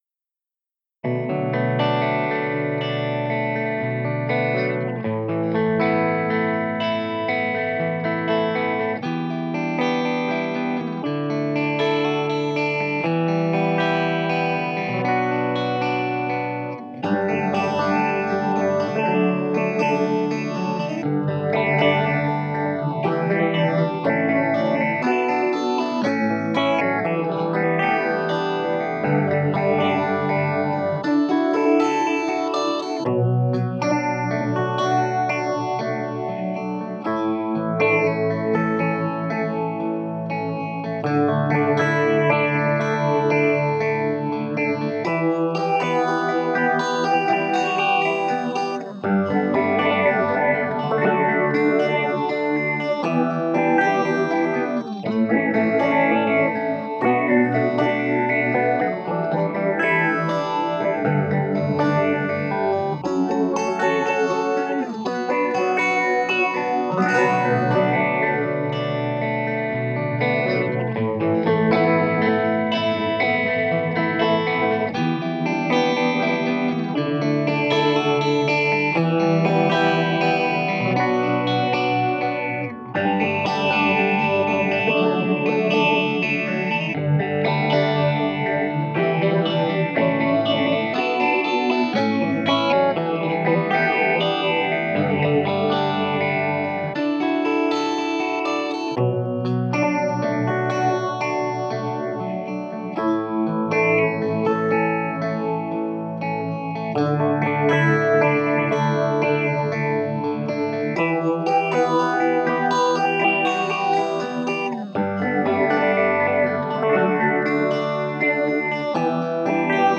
Clean Electric Guitars with 16Stage Phaser
First 18 seconds are dry before FX comes in. Filter Frequency Envelope Mod plus RandomChaotic LFO Filter Mod. Various settings a few bars at a time to sample the possibilities.
Note: The source dry track was generated by PG Music's Band In A Box program.
CPD_16Stage_EGuiatar_EnvModFc.mp3